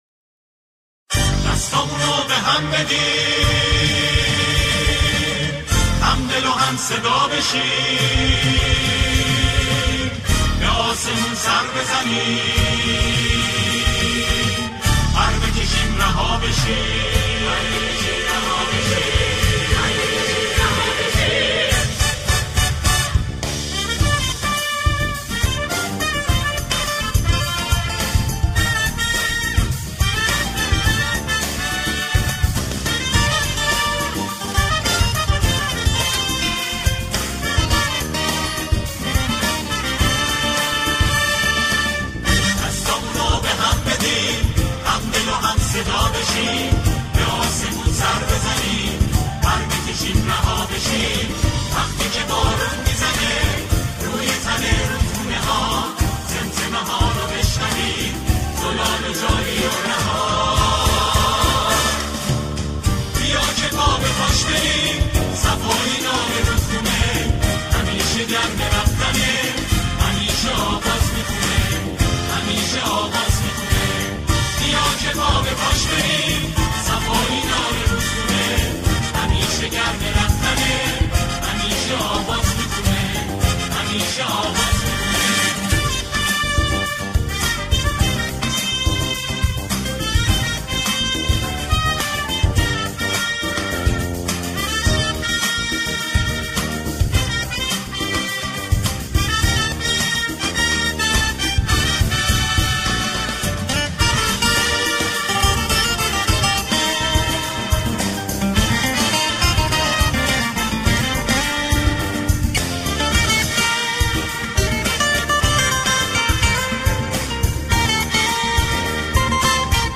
با اجرای گروه کر ساخته شده